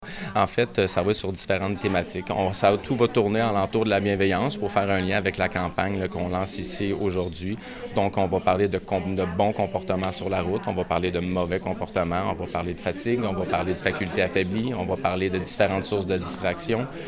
En conférence de presse mardi à l’hôtel de ville, la Ville a présenté trois capsules vidéo sur les bonnes pratiques de conduite à adopter.